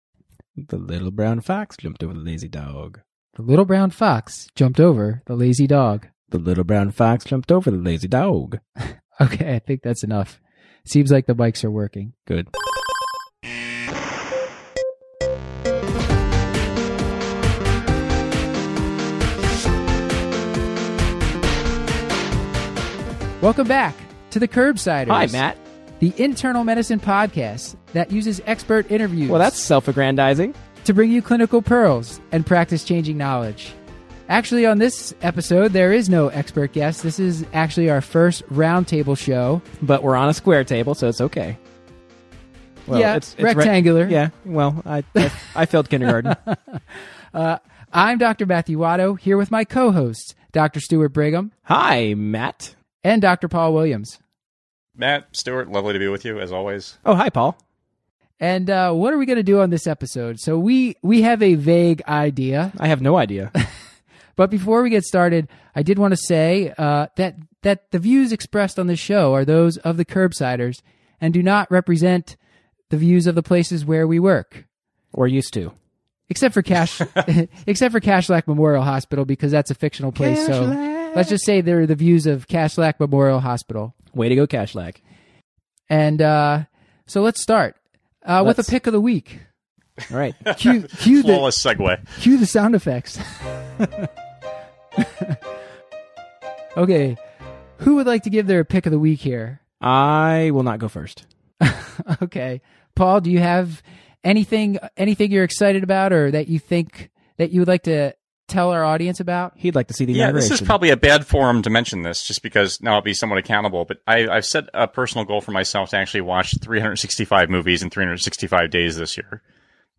More tools, tips, and tricks so you can master obesity in clinic. On this first roundtable episode, The Curbsiders give their take on the management of obesity, and offer their own practice changing tips.